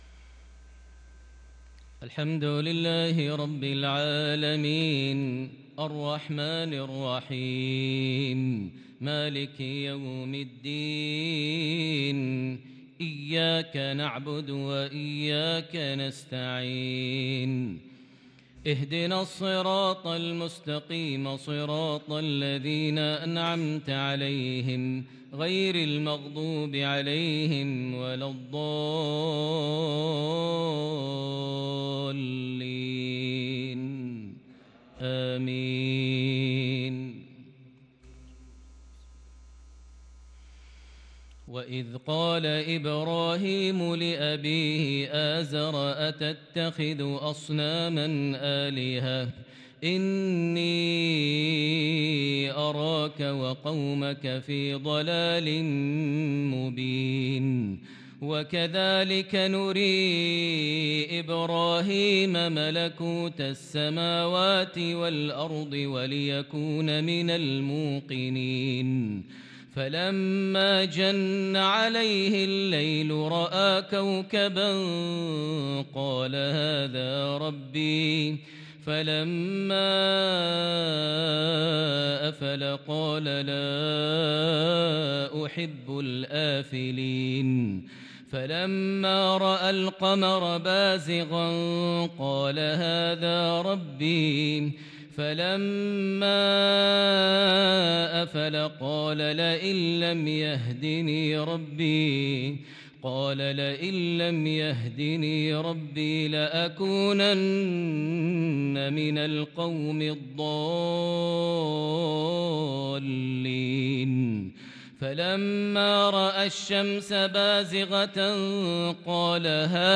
صلاة العشاء للقارئ ماهر المعيقلي 21 ربيع الأول 1444 هـ
تِلَاوَات الْحَرَمَيْن .